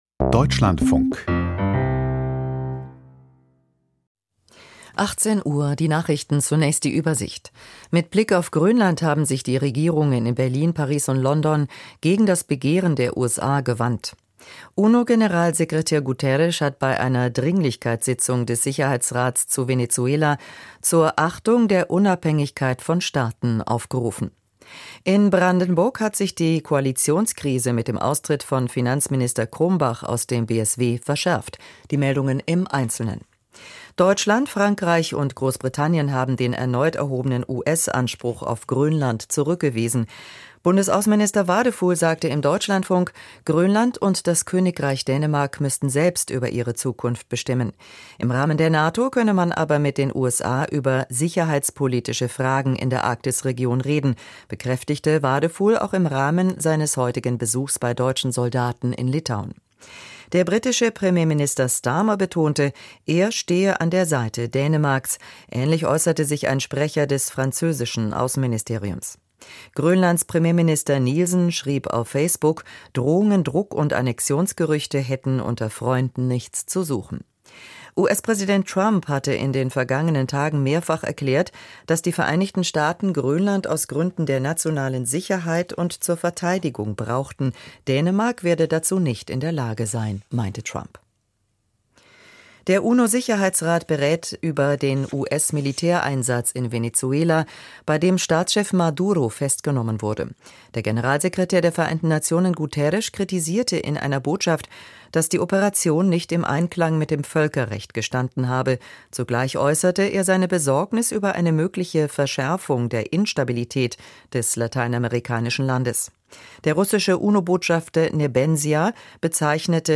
Die Nachrichten vom 05.01.2026, 18:00 Uhr